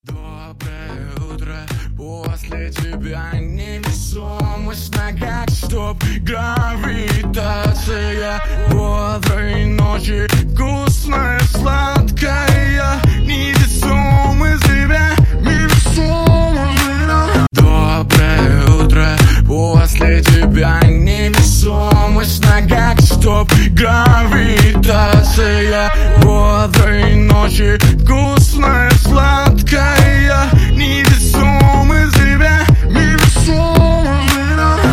• Качество: 128, Stereo
мужской вокал
remix
нарастающие